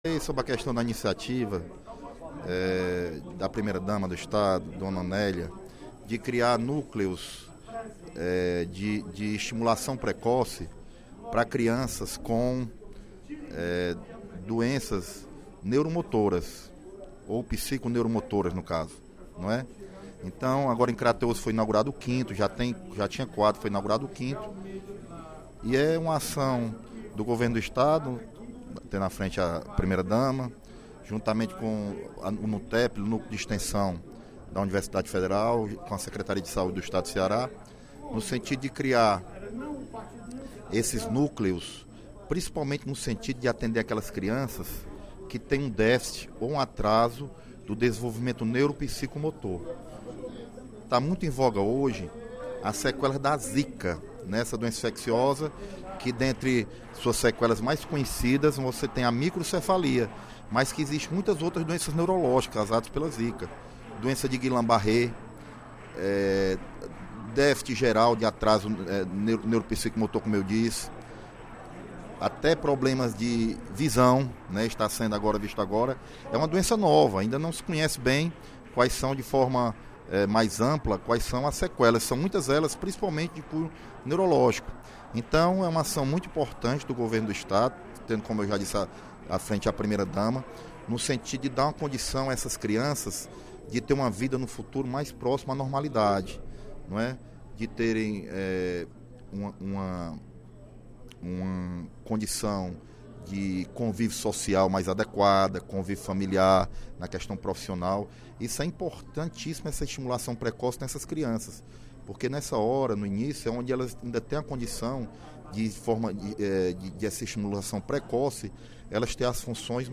O deputado Leonardo Pinheiro (PP) destacou, durante o primeiro expediente da sessão plenária desta terça-feira (05/07), a implantação do Núcleo de Estimulação Precoce do Governo do Estado, na policlínica de Crateús.